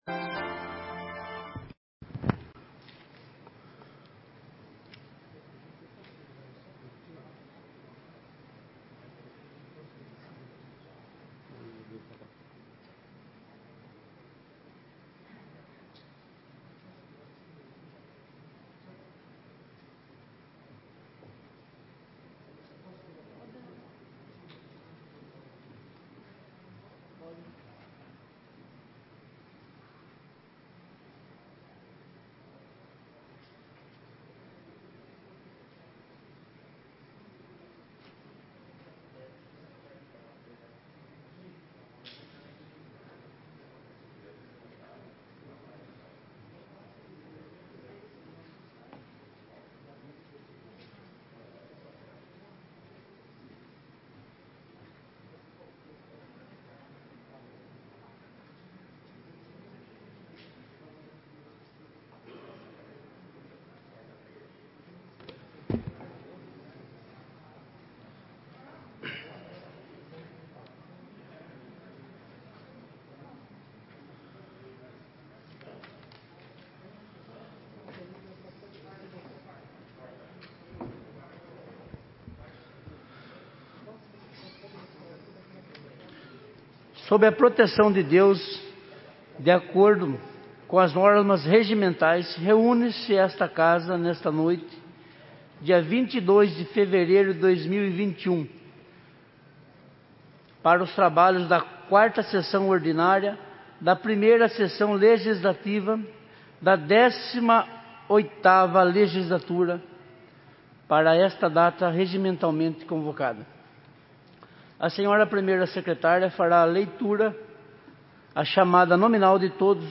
ÁUDIO – Sessão Ordinária 22/02/2021